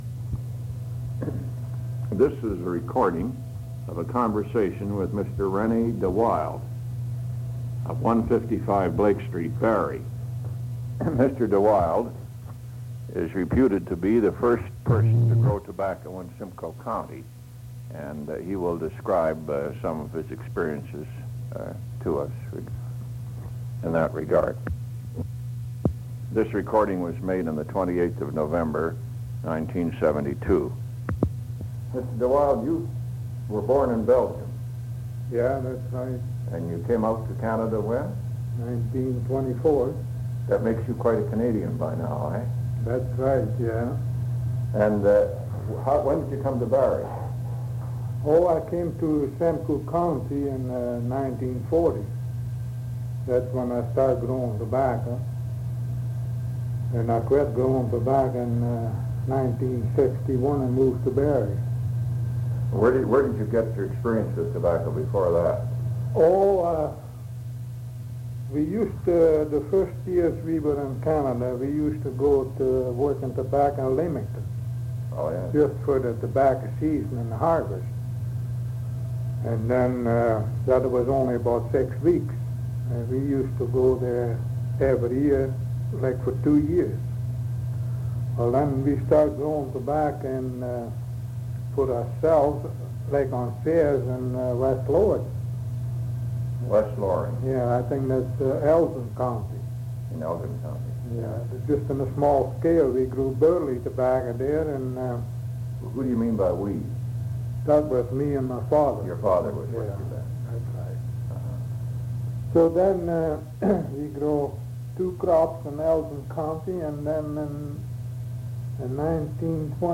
The interviewer is unnamed.
Oral History